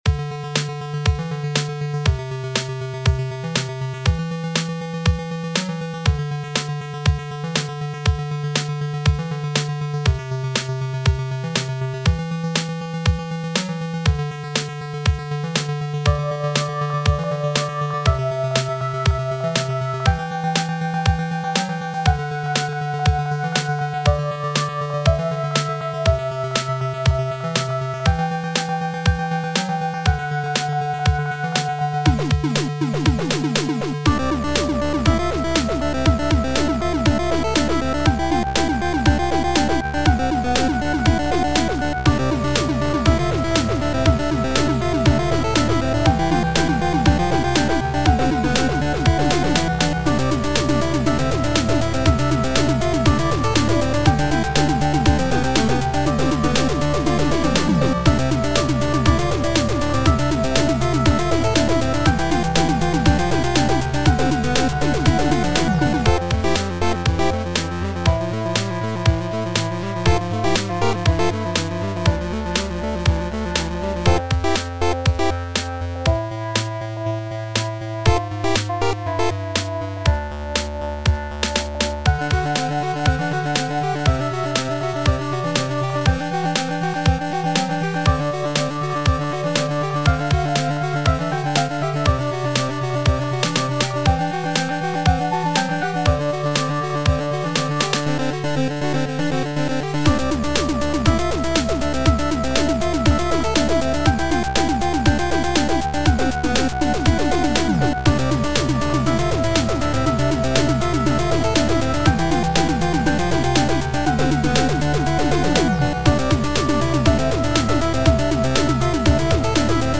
I wanted to make the most 8bit sounding song I could and this is what I came up with.
Â The drums are a simple bass and snare sound,Â with high hats every so often. I didn't use 8bit drums because I wanted to see if I could make it danceable.
I liked the last motif with the chords.